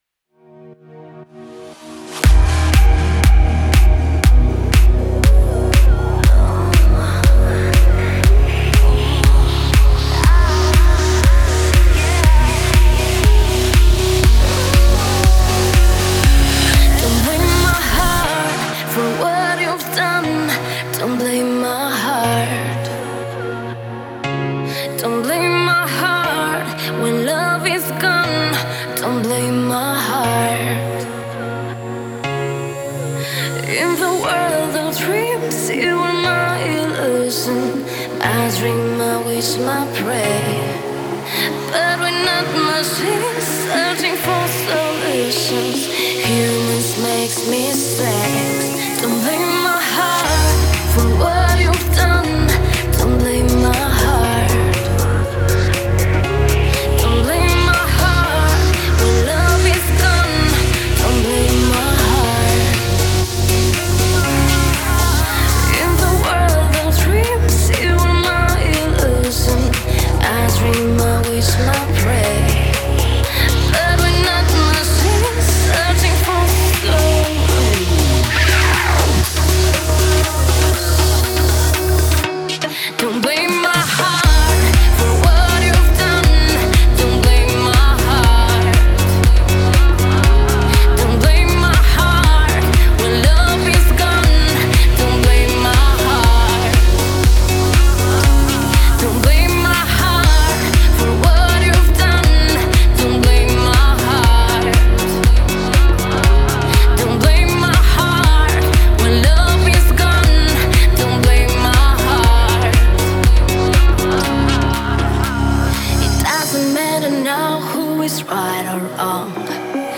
это зажигательная песня в жанре евродэнс